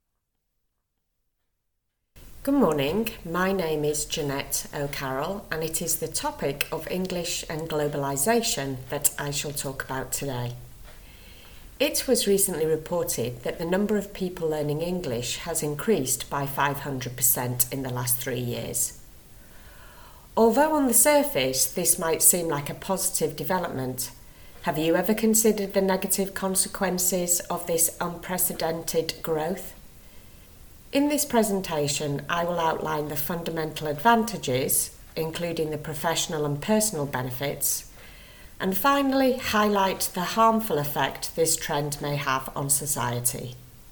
• Exam-ready recorded monologue (MP3)
c1-eoi-monologue-english-and-globalisation-sneak-peek.mp3